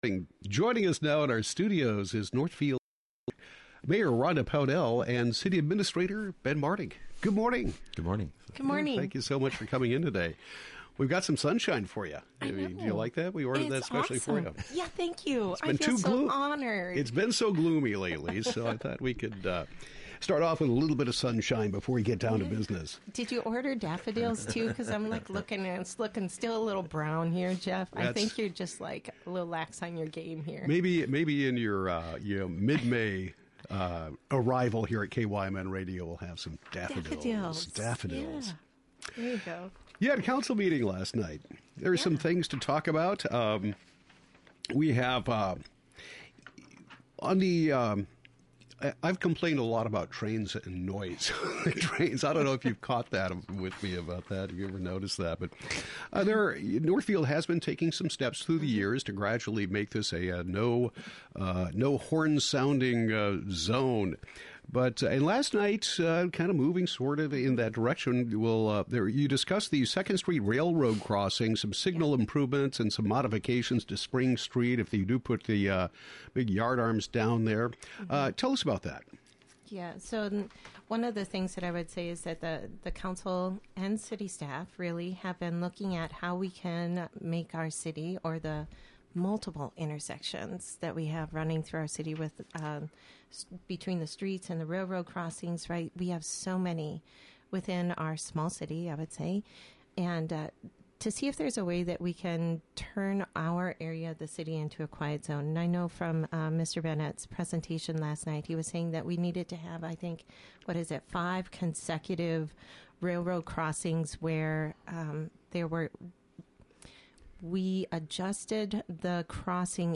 Northfield Mayor Rhonda Pownell and City Administrator Ben Martig discuss the May 3 City Council meeting. Topics include quiet zone for railroad crossings, planning for Bridge Square and 5th and Water Street development projects, building a "brand" for Northfield, and more.